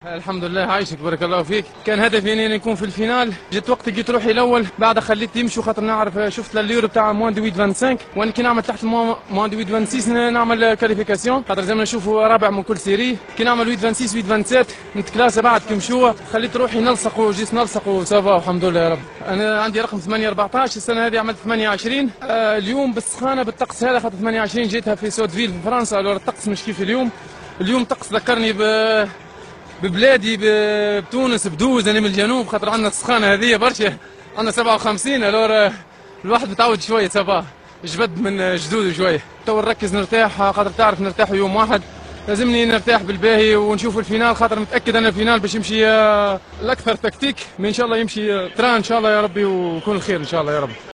تصريح لقنوات بي أين سبور